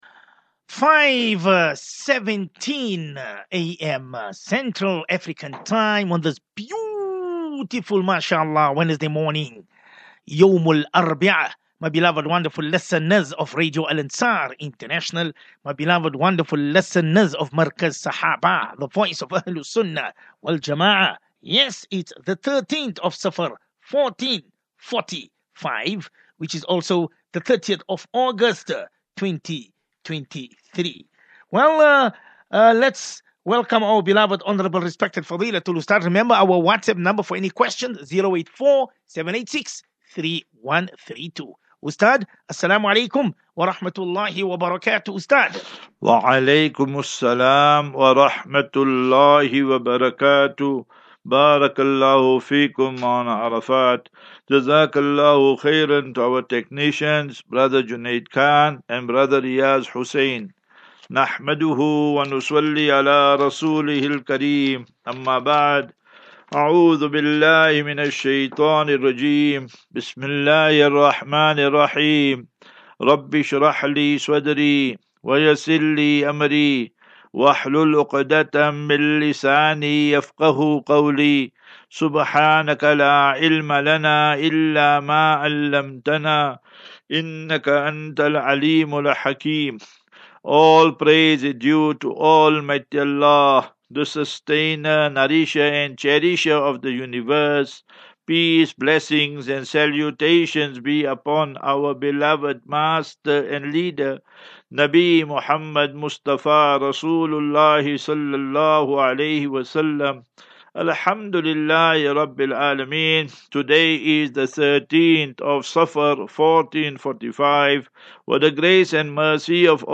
As Safinatu Ilal Jannah Naseeha and Q and A 30 Aug 30 August 2023.